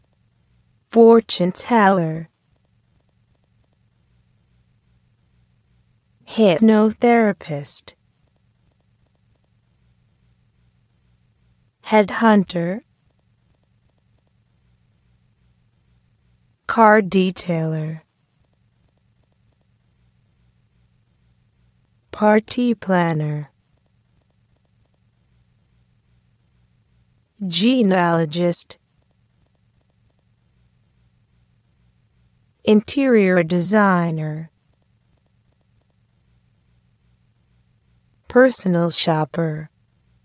This glossary presents occupations or professions that involve providing customers with various kinds of personal services. Please listen and repeat twice.